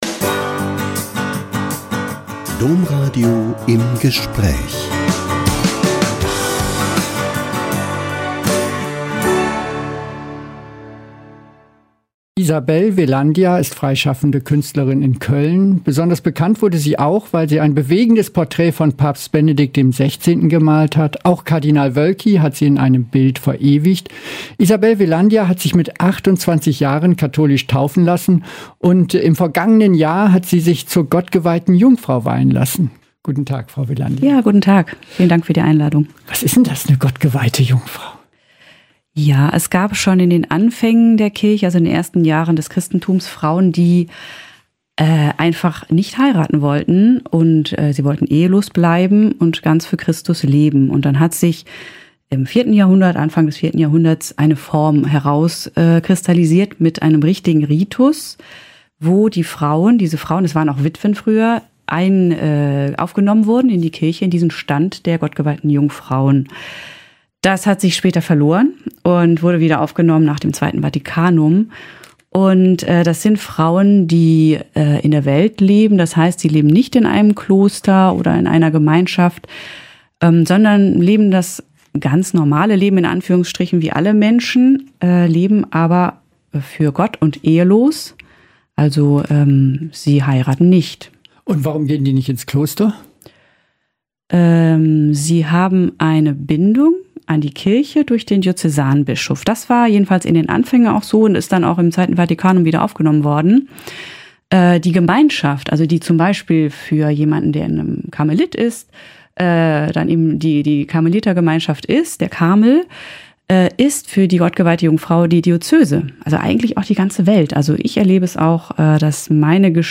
Sie hat sich mit 28 Jahren katholisch taufen lassen und ist seit einem Jahr gottgeweihte Jungfrau. Im Interview erklärt sie, was das heißt und wie es ihren Alltag verändert.